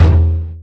drumshooter01.mp3